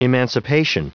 Prononciation du mot emancipation en anglais (fichier audio)
Prononciation du mot : emancipation